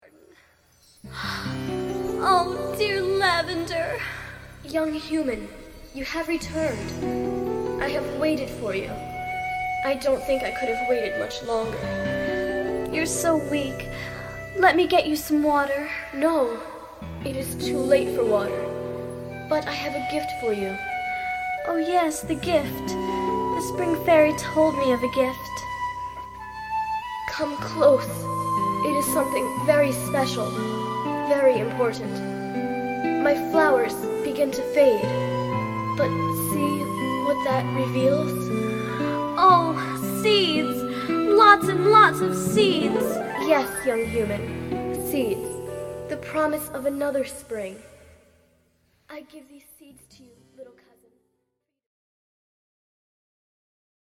Scene+Incidental Music